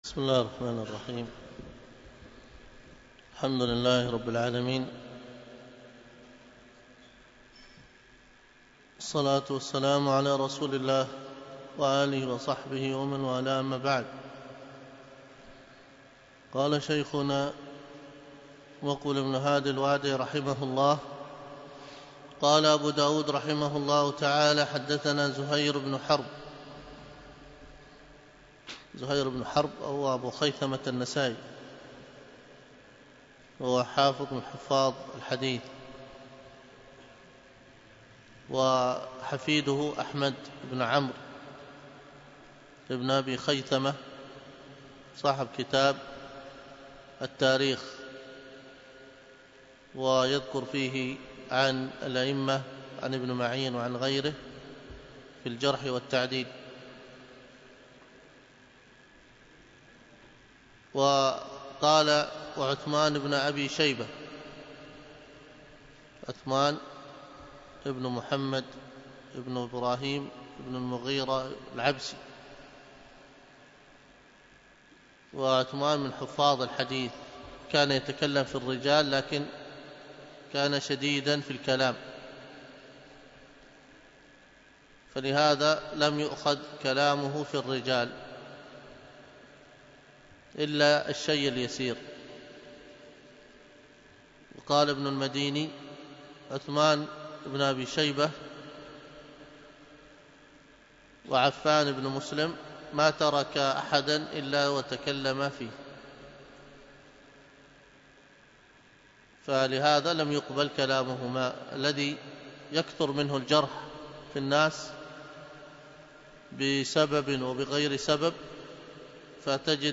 الدرس